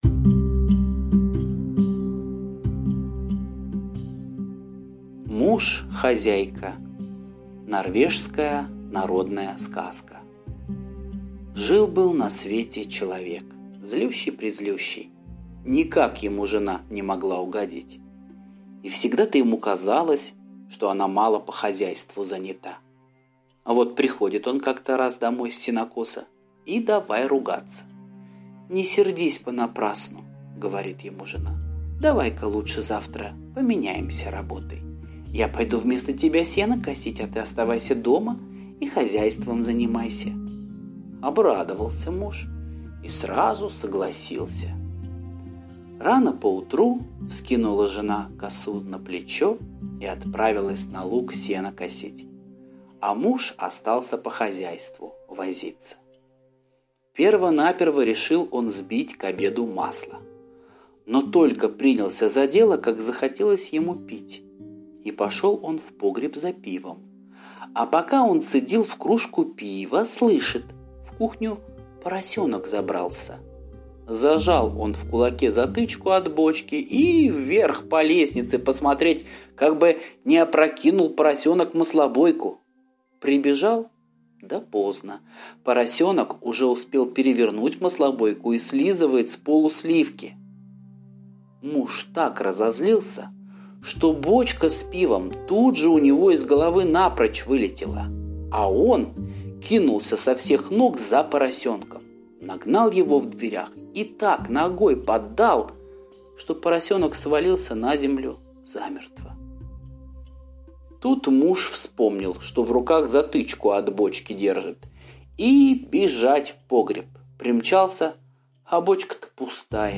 Муж-хозяйка - норвежская аудиосказка - слушать онлайн